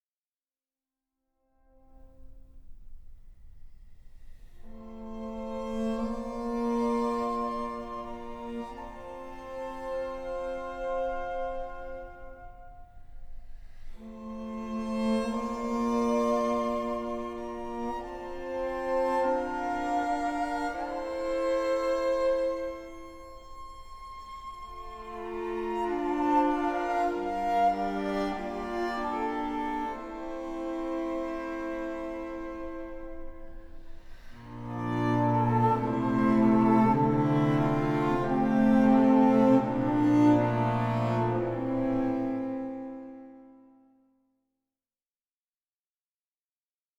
Intemporel, avec une infinie douceur (1.29 EUR)